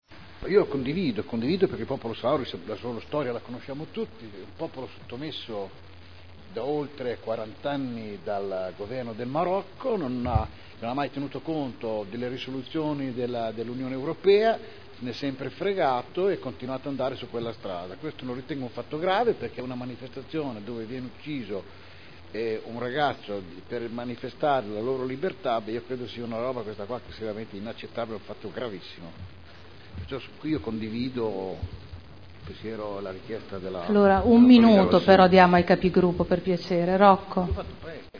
Seduta del 25/10/2010. Dibattito su richiesta di Eugenia Rossi sull'osservanza di un minuto di silenzio per la morte di un ragazzino di 16 anni della città di El Aium, popolo Saharawi, gemellata con la città di Modena